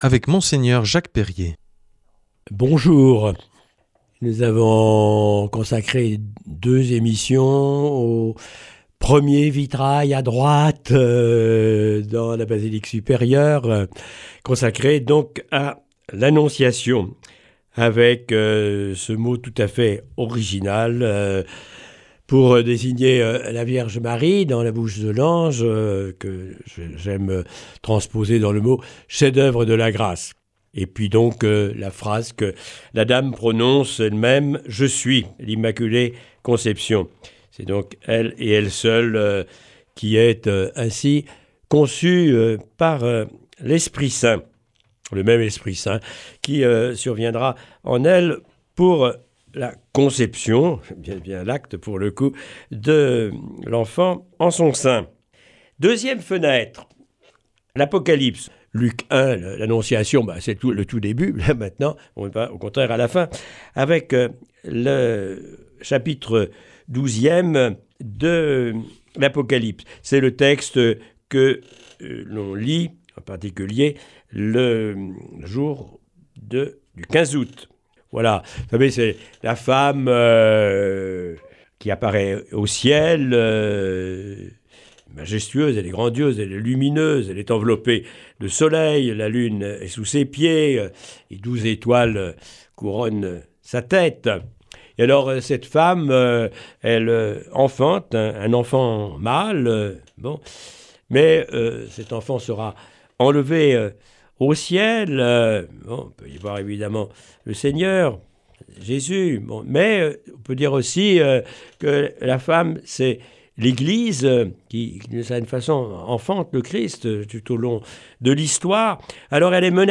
Enseignement Marial du 20 nov.